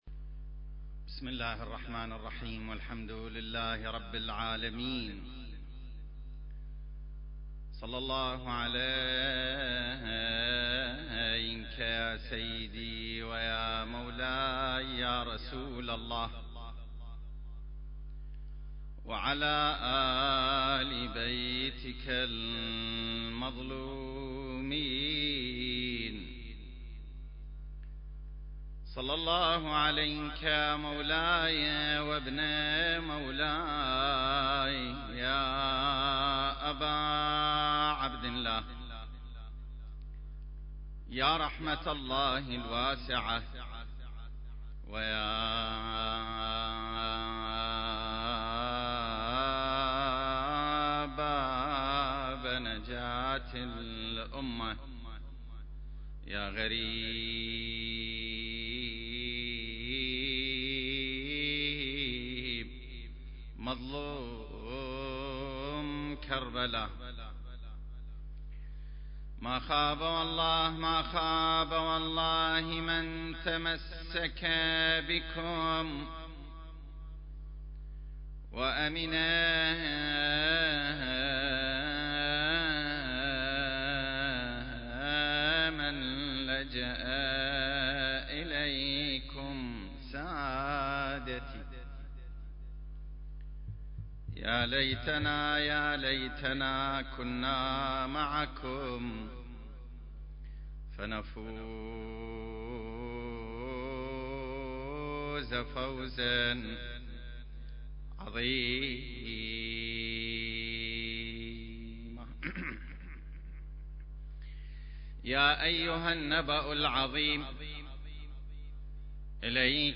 سلسلة محاضرات: نفحات منبرية في السيرة المهدوية المكان